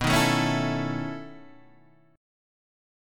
B11 chord {7 6 4 4 5 5} chord